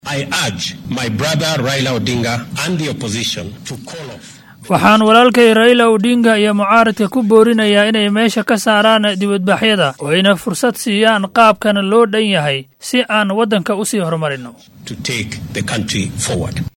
Madaxweynaha dalka William Ruto oo galabta jeediyey khudbad isagoo ku sugan xarunta madaxtooyada ee State House ee magaalada Nairobi ayaa madaxa isbeheysiga mucaaradka ee Azimio La Umoja-One Kenya Raila Odinga ugu baaqay inuu joojiyo dibadbaxyada uu hor kacaya ee ka socdo wadanka.